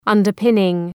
Προφορά
{,ʌndər’pınıŋ} (Ουσιαστικό) ● θεμέλια